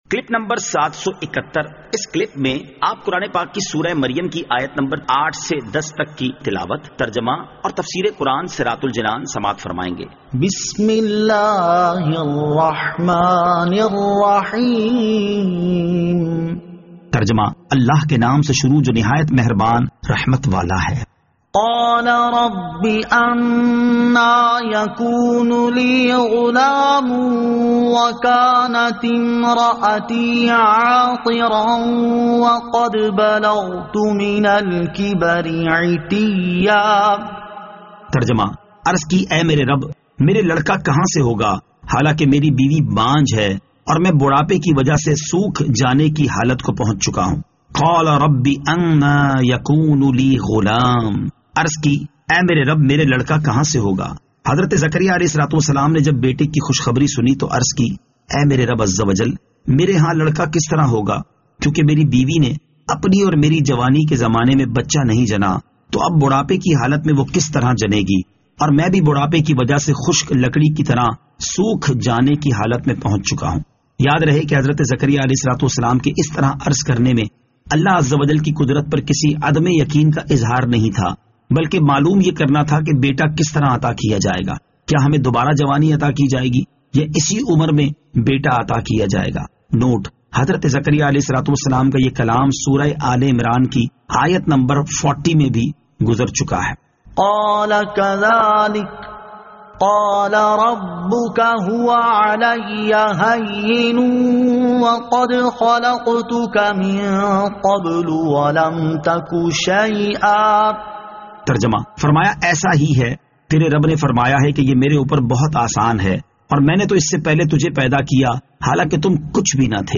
Surah Maryam Ayat 08 To 10 Tilawat , Tarjama , Tafseer